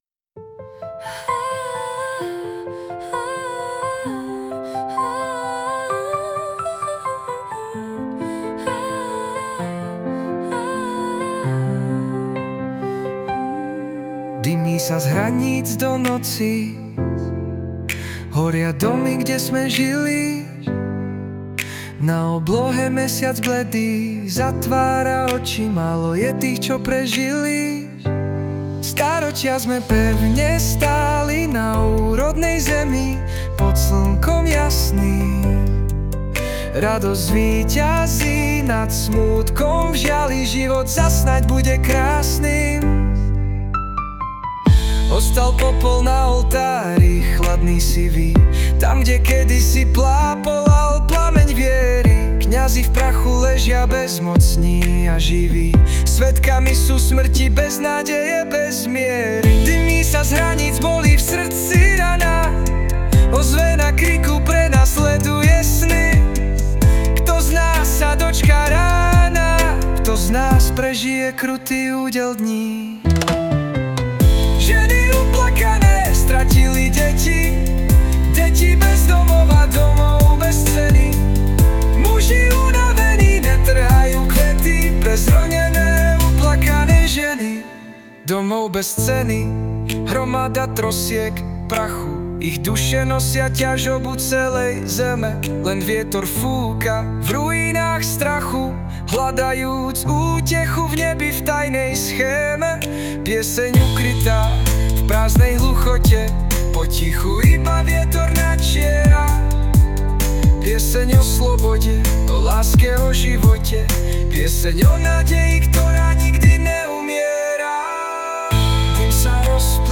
Hudba a spev AI
Je ľúbozvučná...i keď... tie dva verše o kňazoch mi trošku nesedia...oni sú nosičmi svetla a nádeje... prevažne.